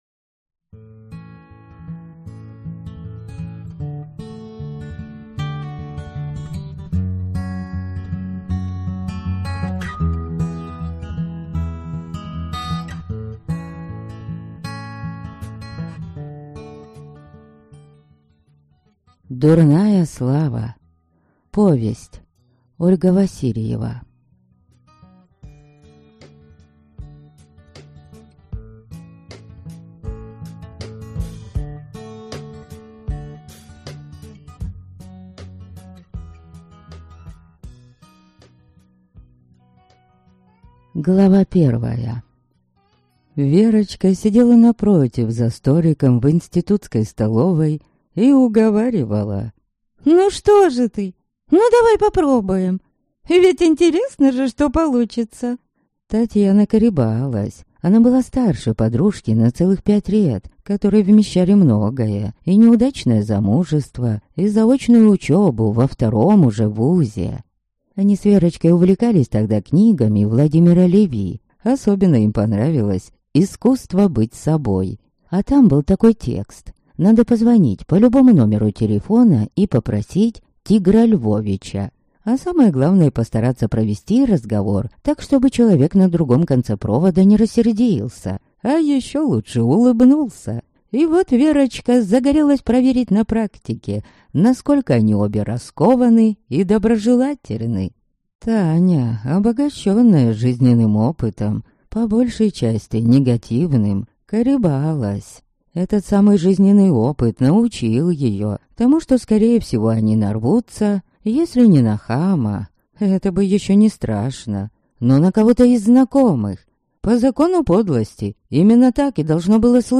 Аудиокнига Дурная слава (повесть) | Библиотека аудиокниг